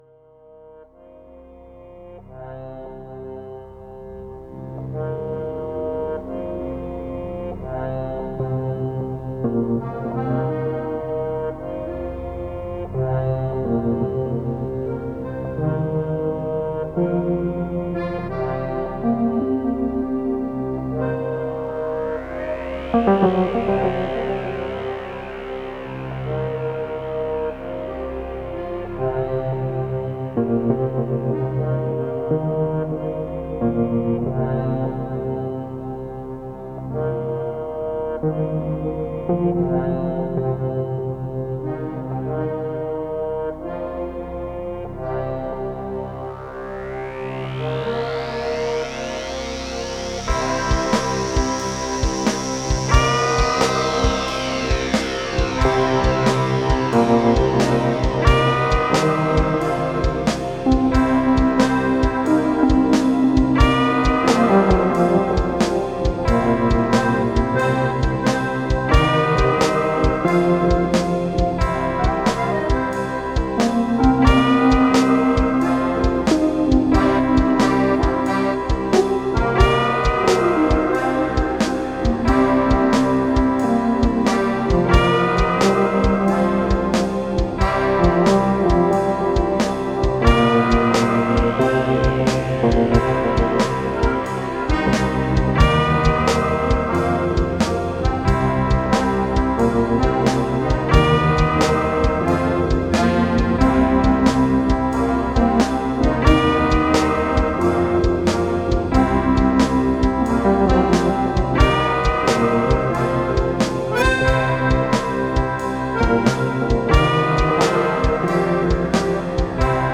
Dark amtosphere. Folk Accordeon pending with guitar.